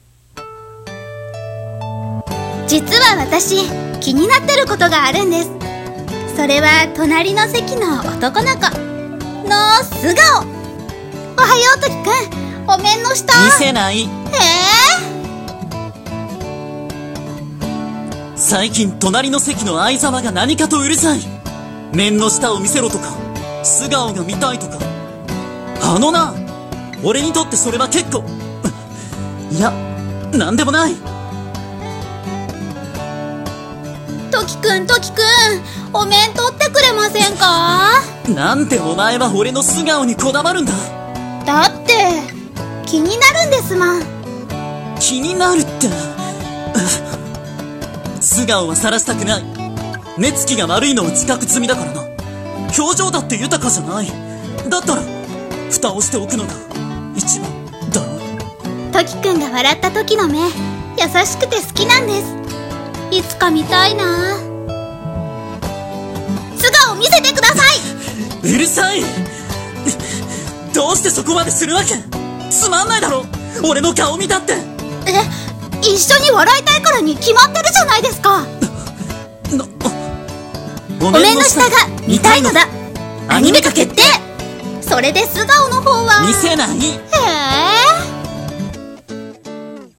【CM風声劇】お面の下が見たいのだ